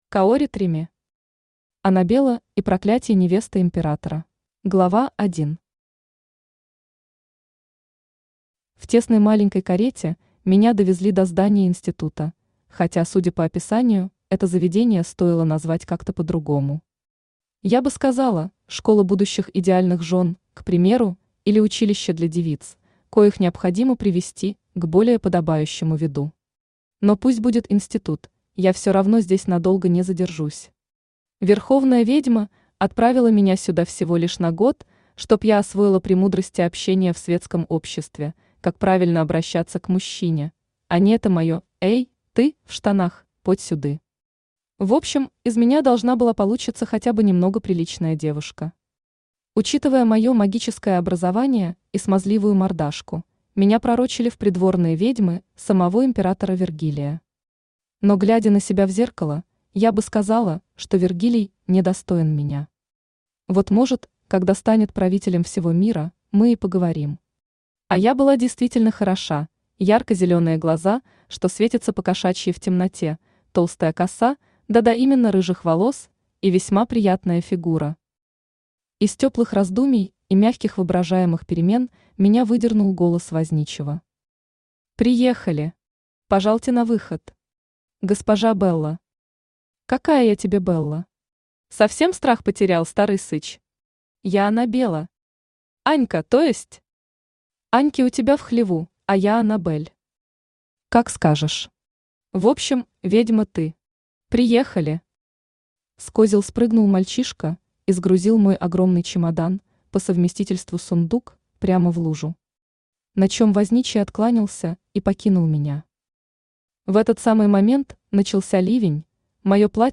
Аудиокнига Анабелла и проклятье невесты императора | Библиотека аудиокниг
Aудиокнига Анабелла и проклятье невесты императора Автор Каори Треми Читает аудиокнигу Авточтец ЛитРес.